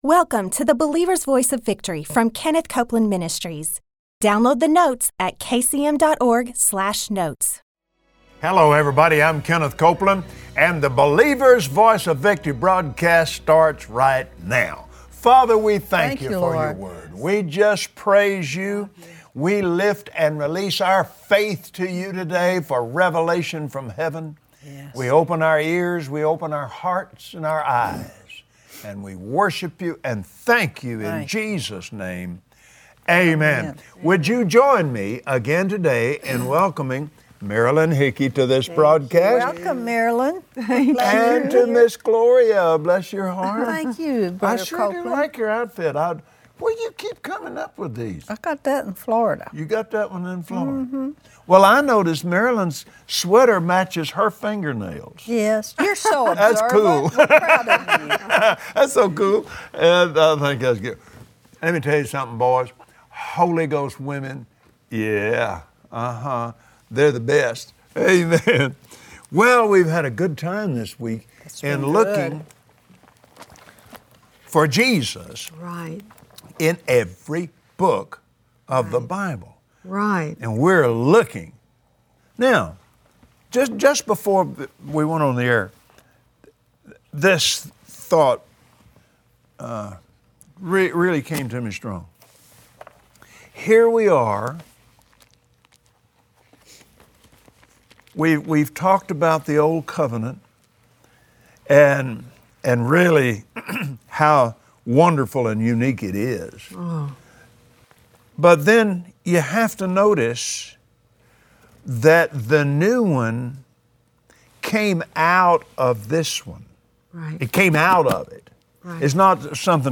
Kenneth and Gloria Copeland with their guest